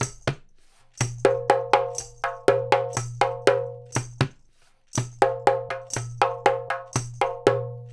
121-PERC1.wav